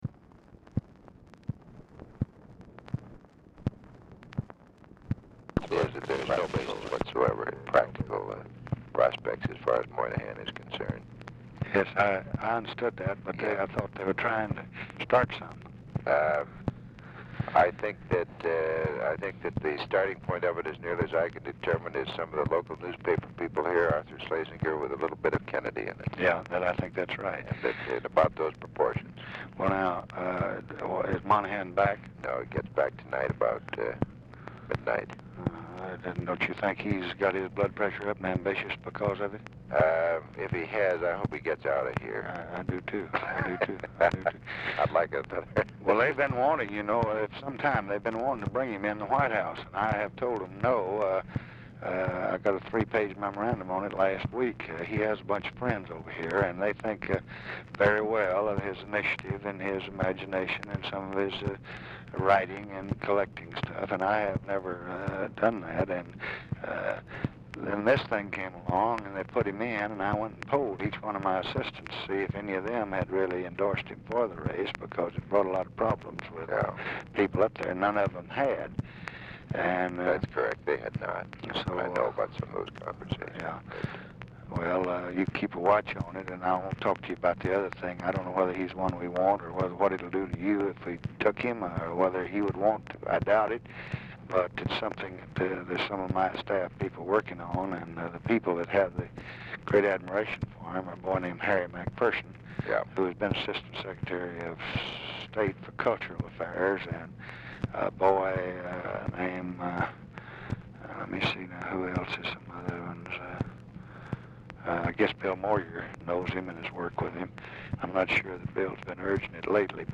Telephone conversation # 8193, sound recording, LBJ and WILLARD WIRTZ, 6/24/1965, 5:30PM | Discover LBJ
RECORDING STARTS AFTER CONVERSATION HAS BEGUN; CONTINUES ON NEXT RECORDING
Format Dictation belt
Location Of Speaker 1 Mansion, White House, Washington, DC